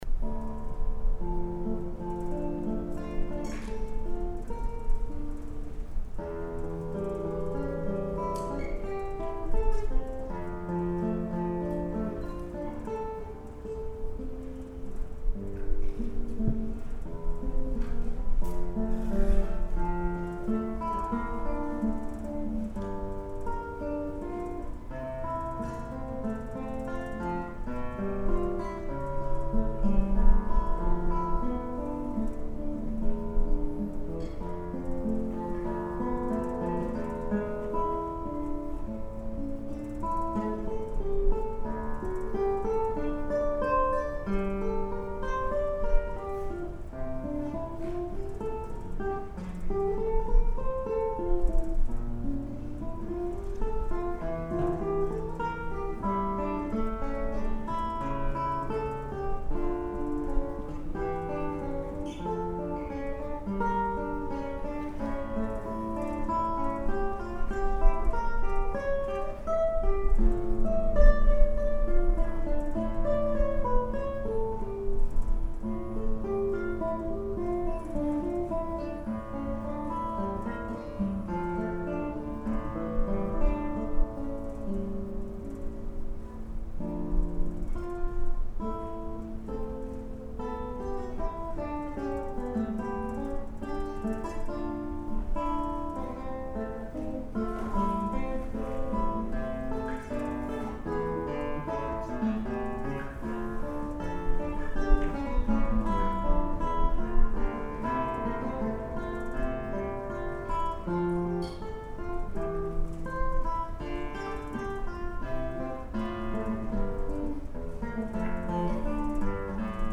ギターコンサート
solo